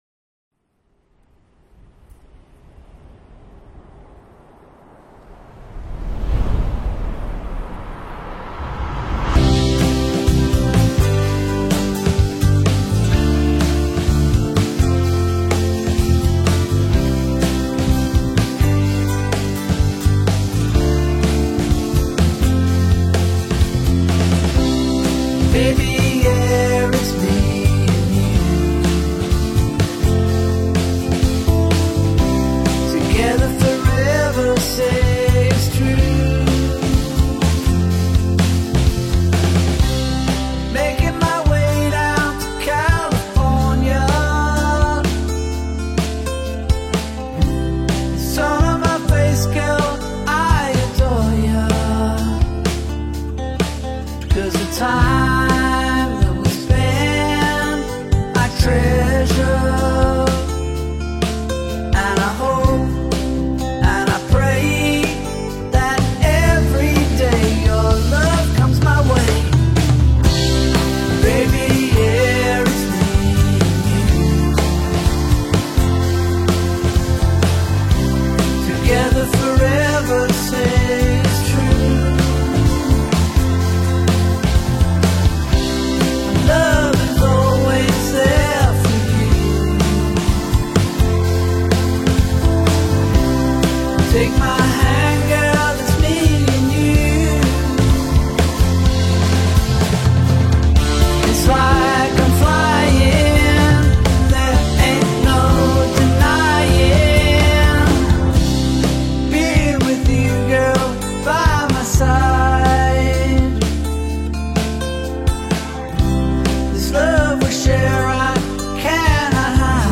It is personal without being overly precious.